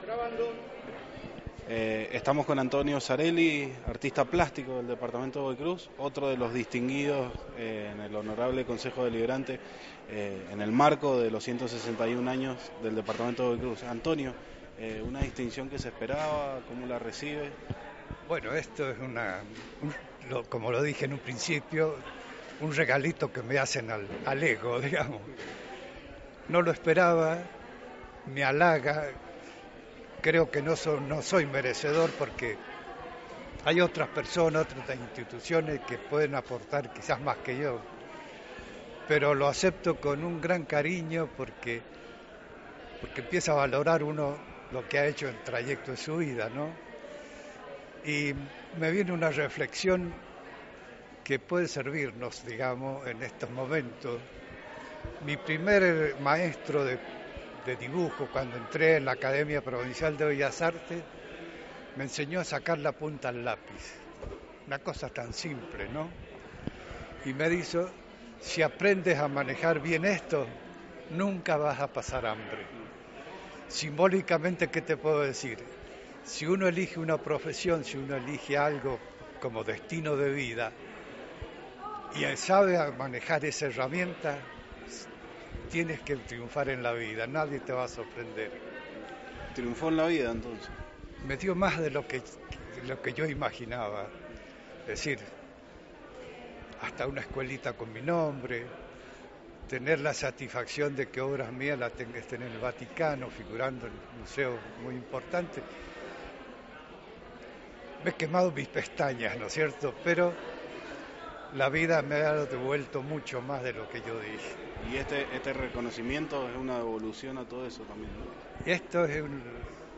La distinción para Vecino Honorable se realizó en el HCD de Godoy Cruz.